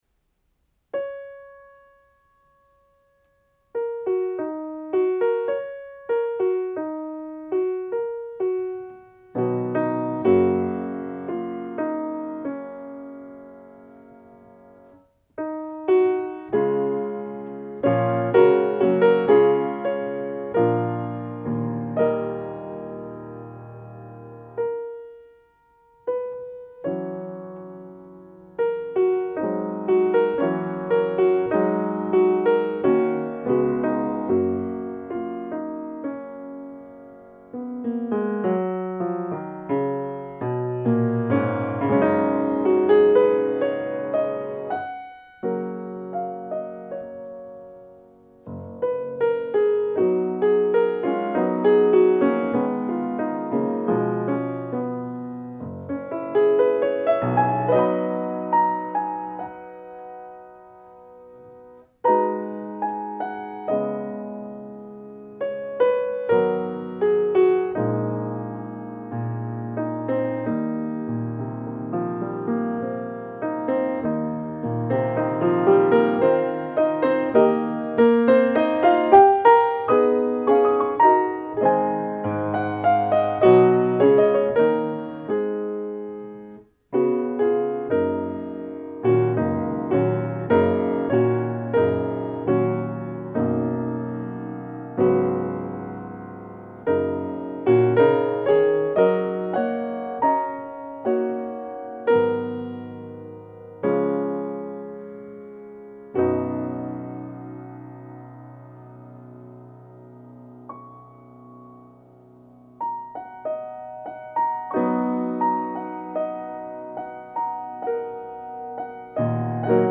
1922 Steinway & Sons Model M
Claude Debussey - The Girl With The Flaxen Hair performed on this piano.
-Restored Original Soundboard and Bridges